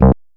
SYNTH BASS-1 0005.wav